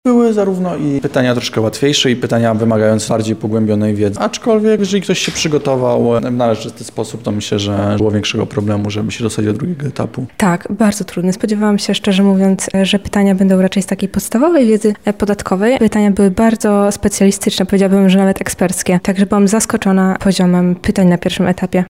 Zapytaliśmy studentów i studentki o to, jak wspominają tamte zmagania:
studenci sonda